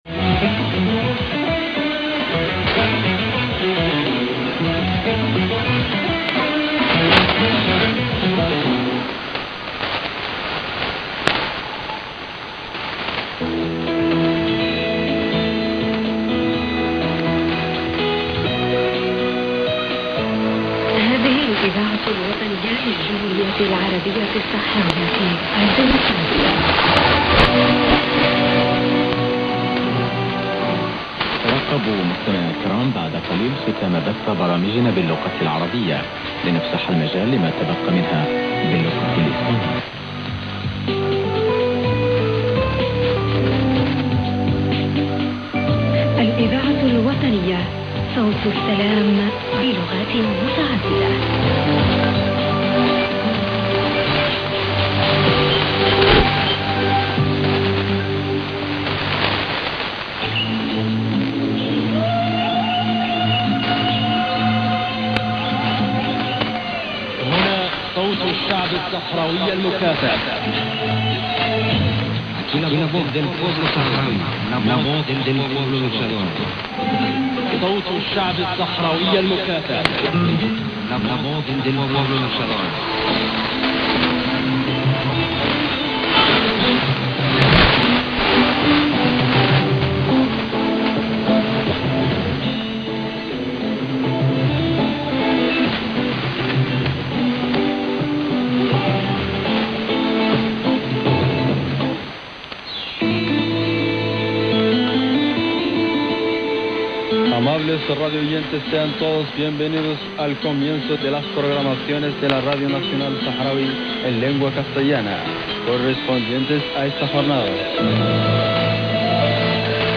.02 music programme in Spanish incl Eagles